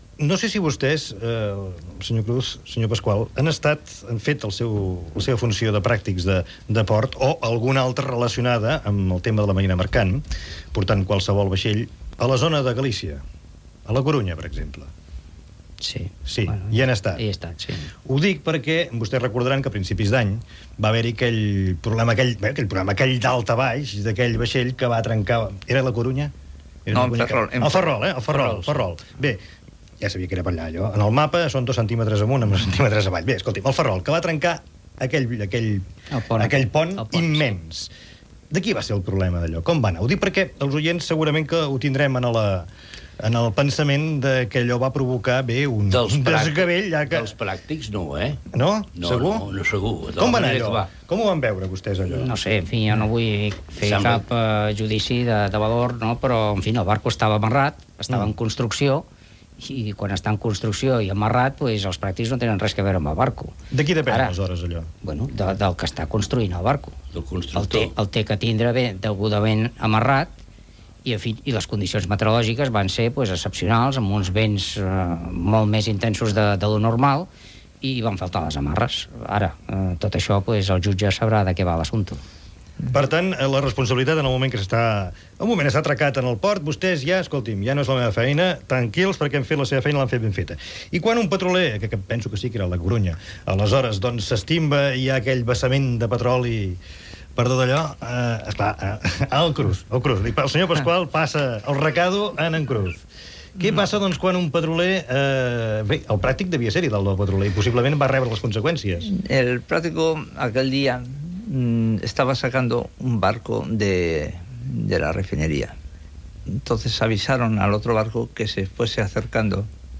Careta de sortida, tema musical i sintonia de l'emissora.
Divulgació